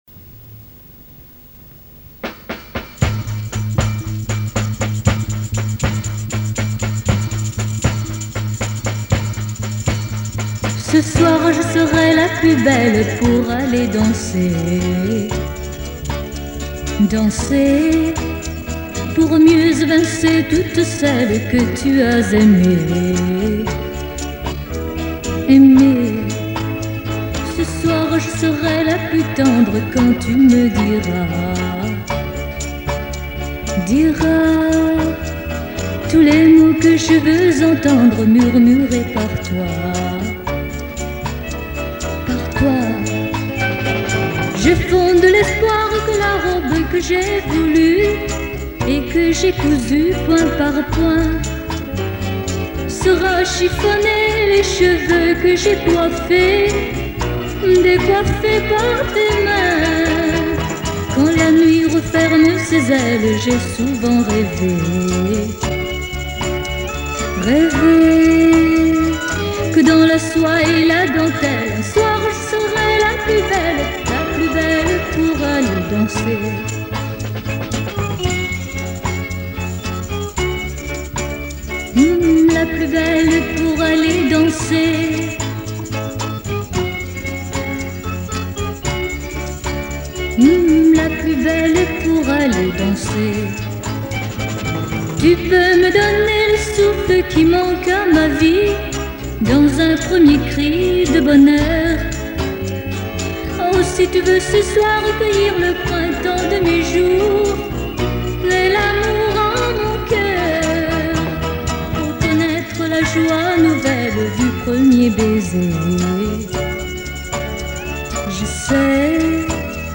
Nhạc Pháp